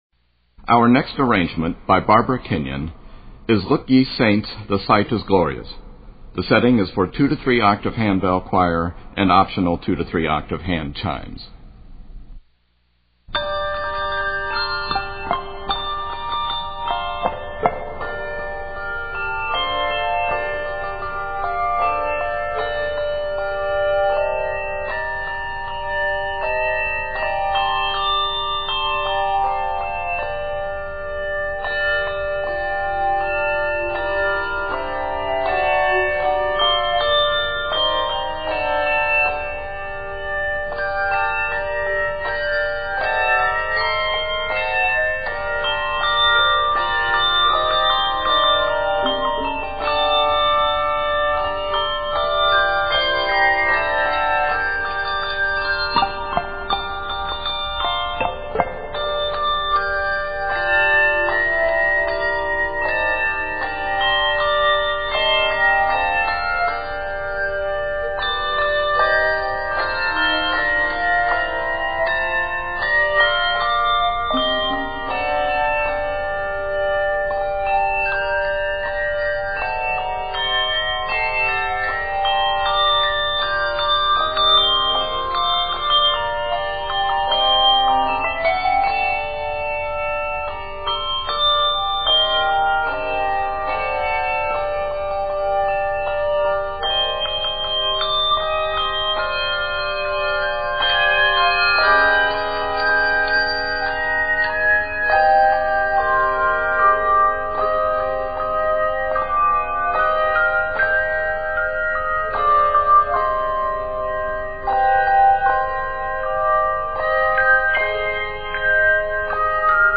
Octaves: 2-3